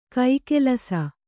♪ kai kelasa